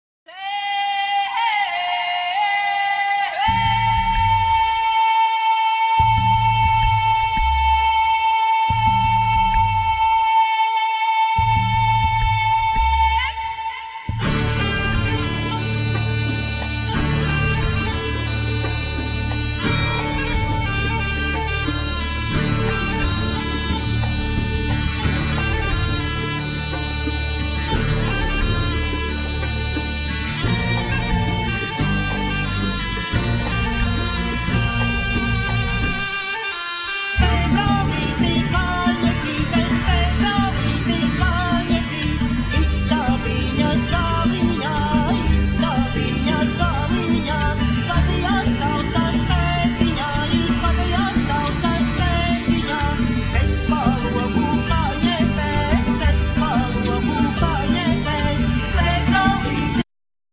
Vocal,Violin,Fiddle,Kokle
Vocal,Giga
Vocal,Kokle,Bagpipe,Acordeon
Guitar
Bungas,Sietins,Bodrans
Programming,Bass,Giga
Mazas bungas